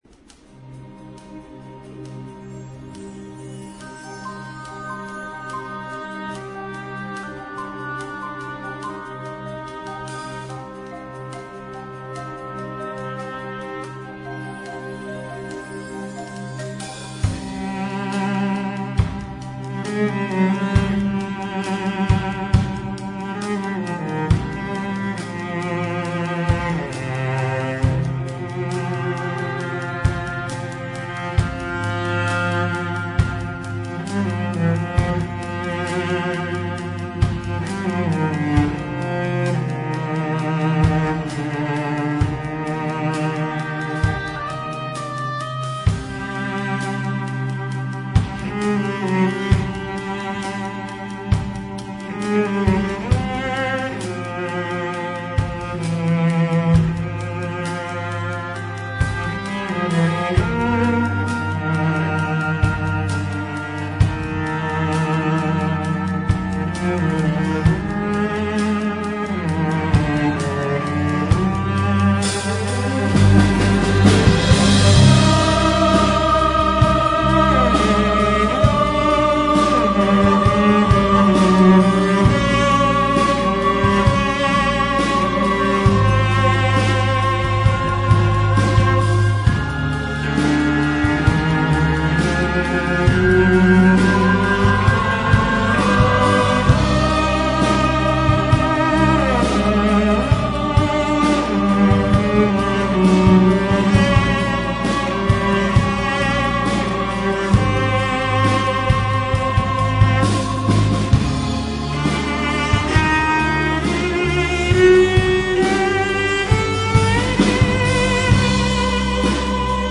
Cellist